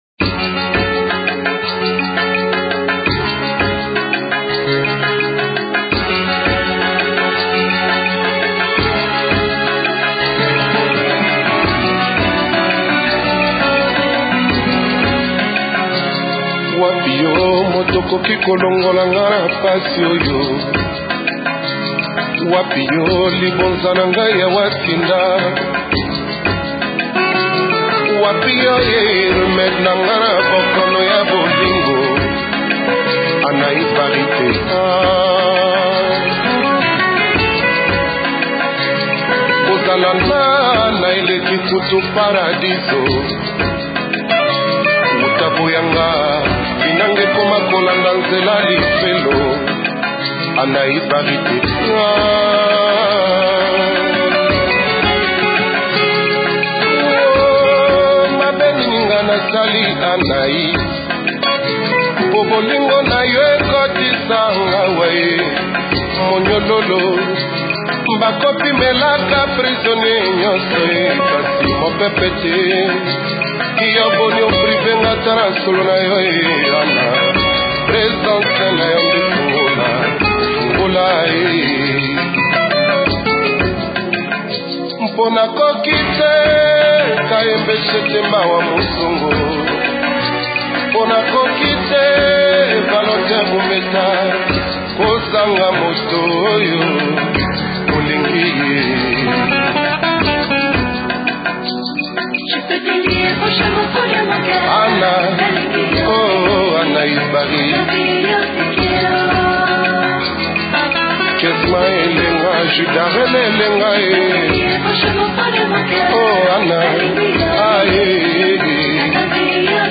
une chanson caractérisée par une mélodie mélancolique